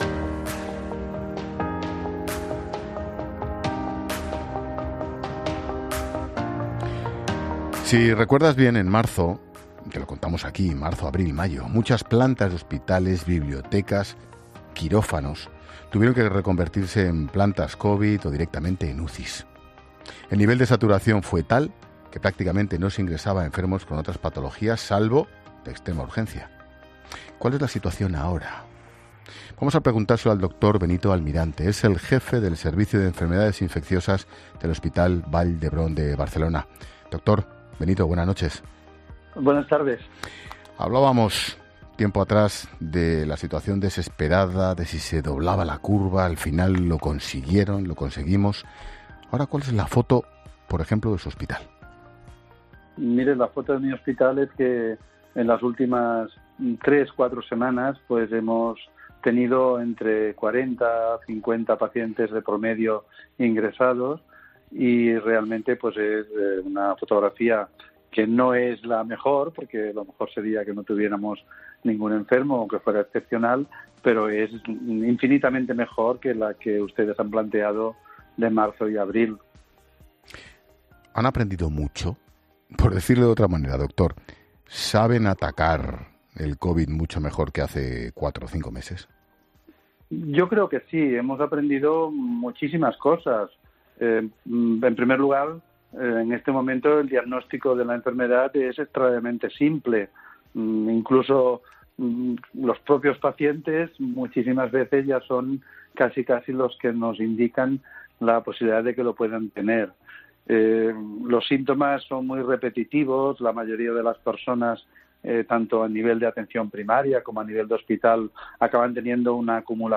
ha pasado por los micrófonos de La Linterna de COPE para valorar la situación .